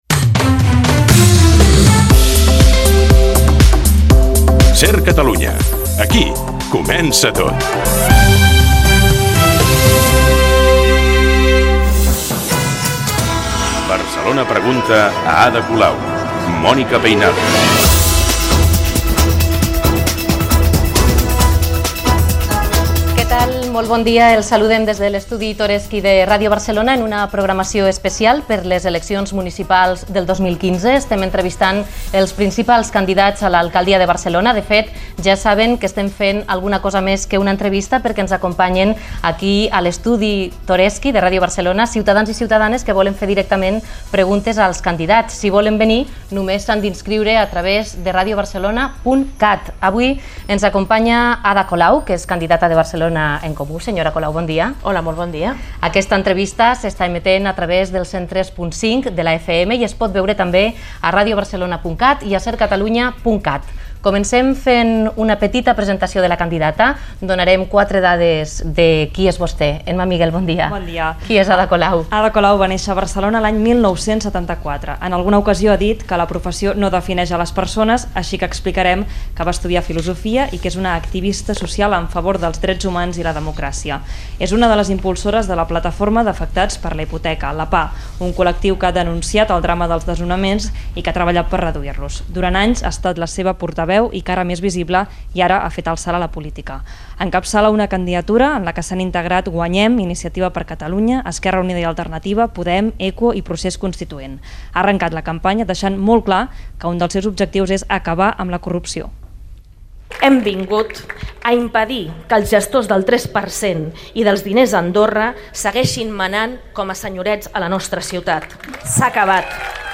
Indicatiu de l'emissora i del programa, llocs per on es pot escoltar i veure el programa, presentació i entrevista a la candidata a l'alacadia de Barcelona Ada Colau de Barcelona En Comú
Informatiu